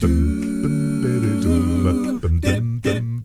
ACCAPELLA 9B.wav